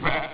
Schafsounds
kurz und knapp:       Möh !!                          (wav 3 KB)
kurzmaeh.wav